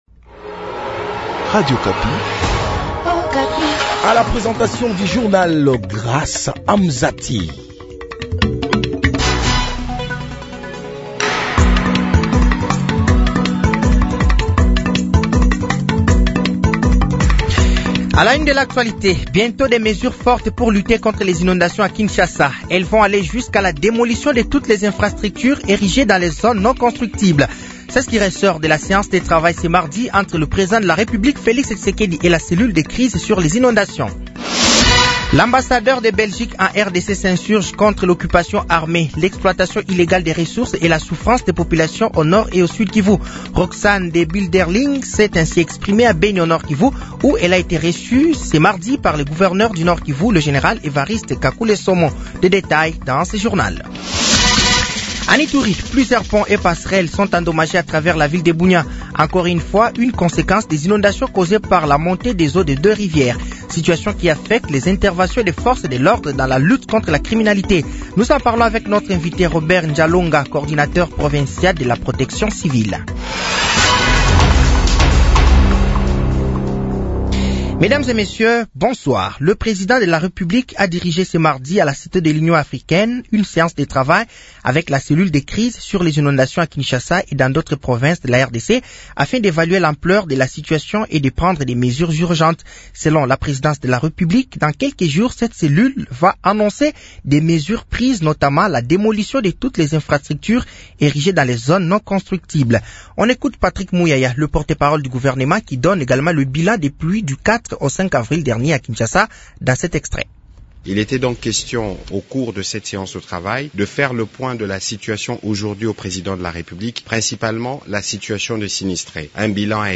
Journal français de 18h de ce mercredi 16 avril 2025